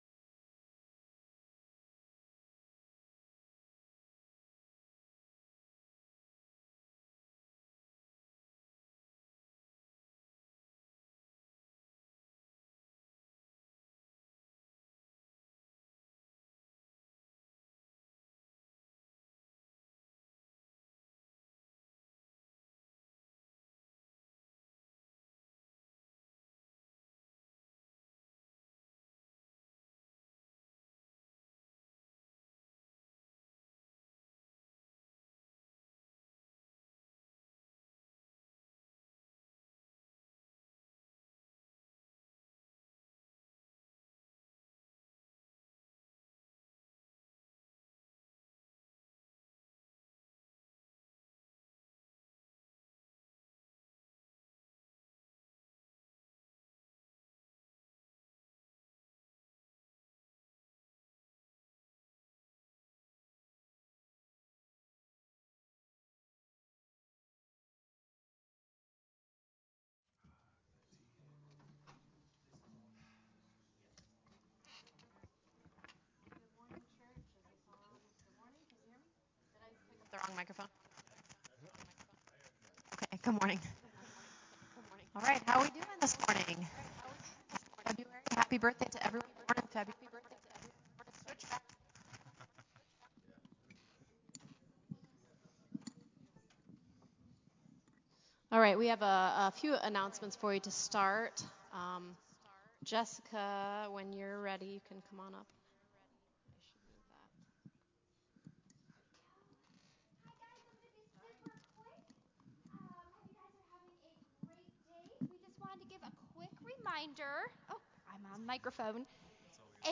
Faith – Saved, Sanctified, and Sealed Sermon